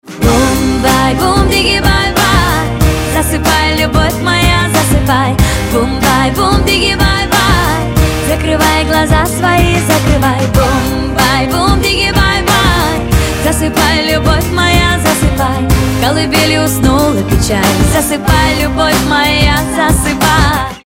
• Качество: 320, Stereo
красивые
женский вокал
спокойные
приятные
колыбельная